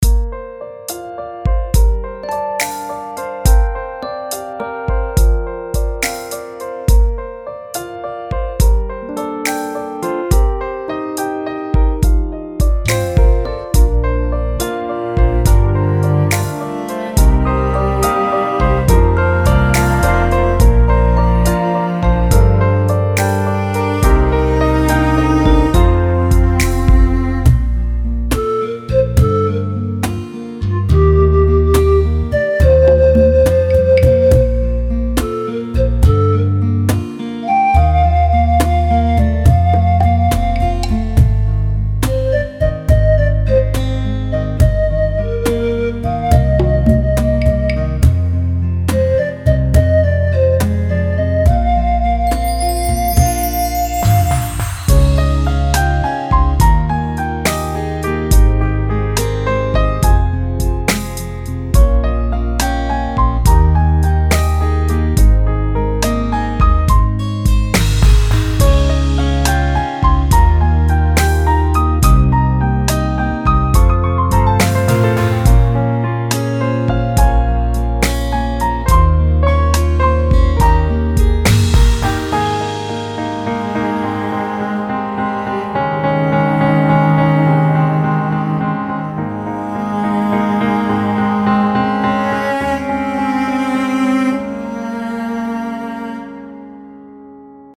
那么这首带有“温度”的旋律一定能让你暖暖哒~